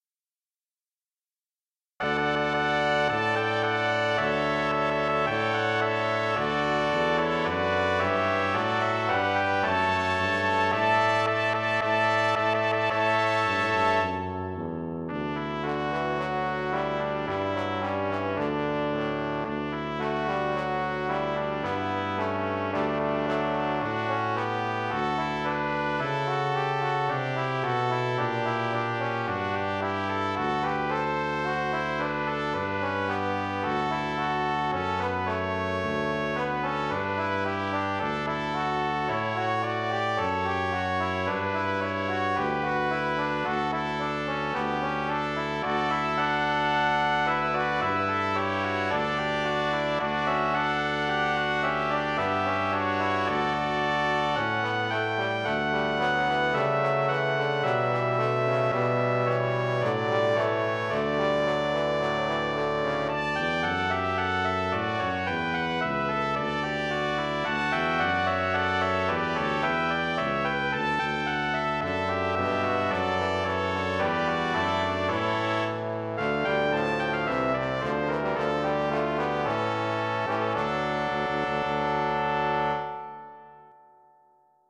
Computer realized
Draco Regno Victory March